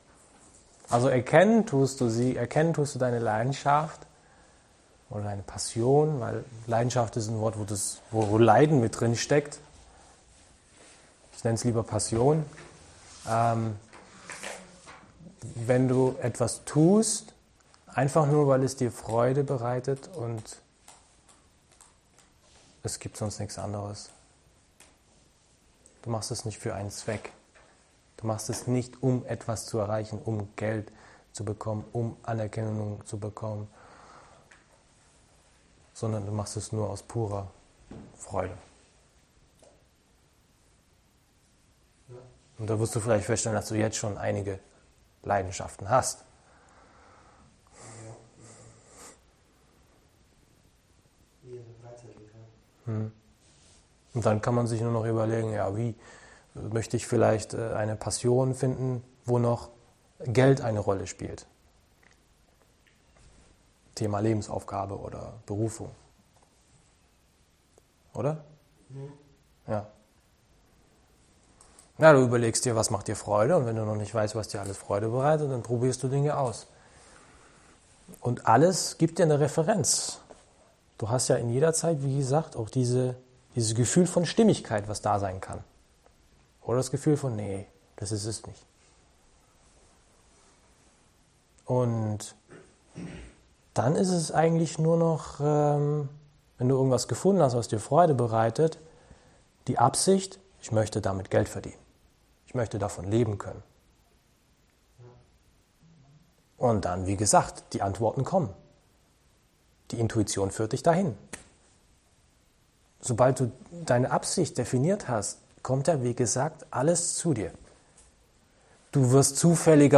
In dieser Audioaufnahme von einem meiner Selbstverwirklichungs-Workshops erzähle ich, wie du deine Leidenschaft erkennen kannst.